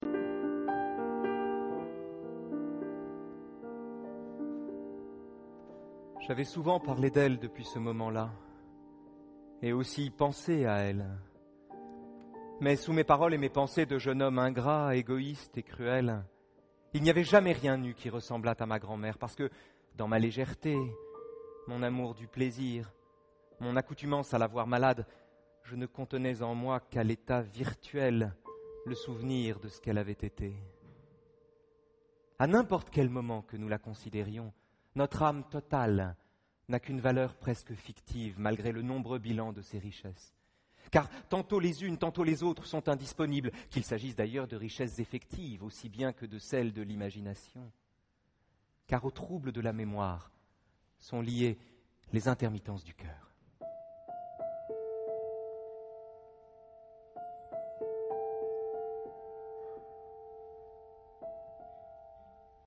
Lectures musicales: Les intermittences du cœur de Marcel Proust.